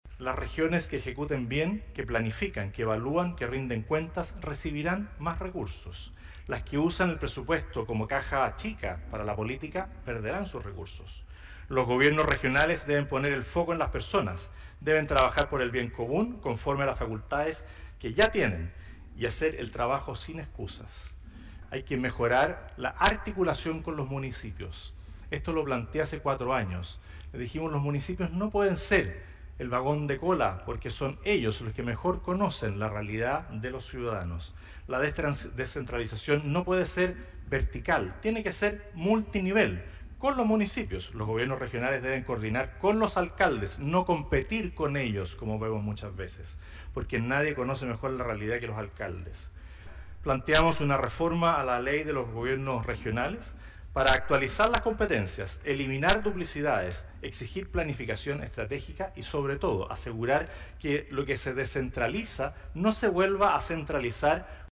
“Descentralización para avanzar” fue el lema de la Cumbre de las Regiones 2025, realizada este lunes en el Teatro Biobío y organizada conjuntamente por Corbiobío, el Gobierno Regional, Desarrolla Biobío y la Asociación de Gobernadores y Gobernadoras Regionales de Chile (Agorechi).